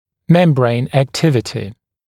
[‘membreɪn æk’tɪvətɪ][‘мэмбрэйн эк’тивэти]активность мембраны